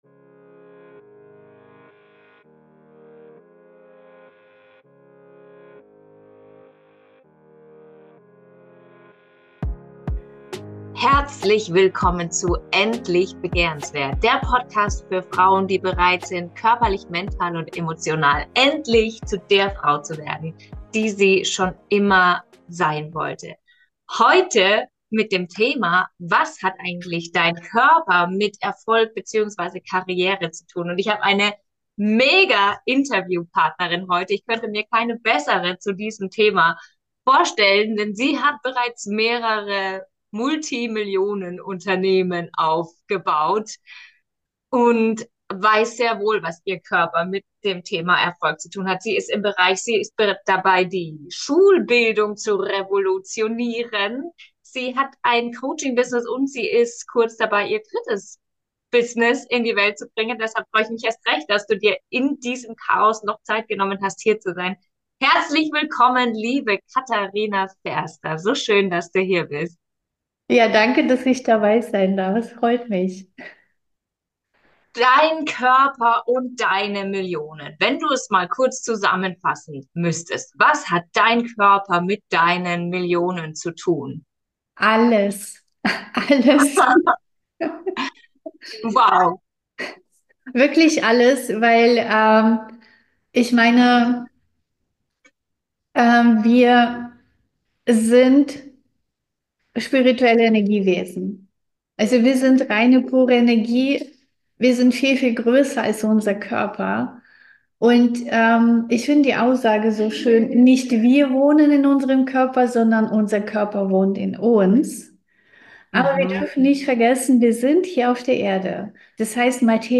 023.Warum der Körper beim Manifestieren die entscheidene Rolle spielt - Interview